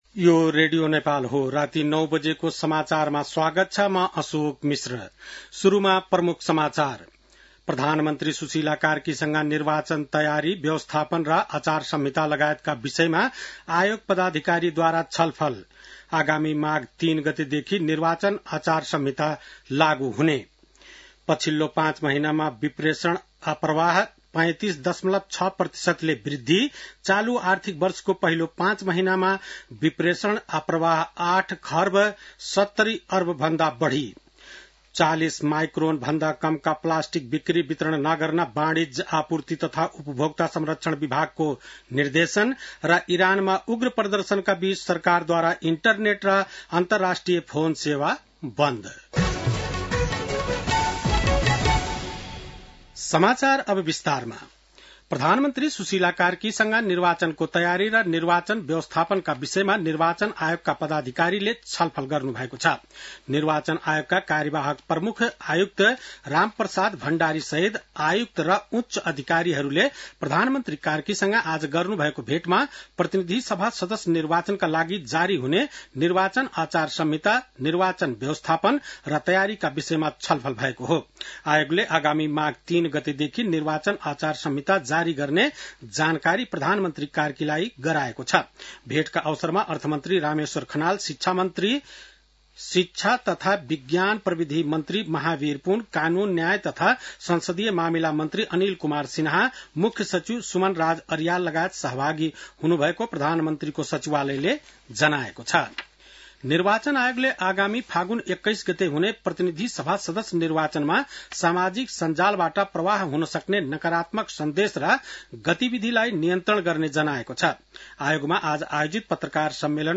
बेलुकी ९ बजेको नेपाली समाचार : २५ पुष , २०८२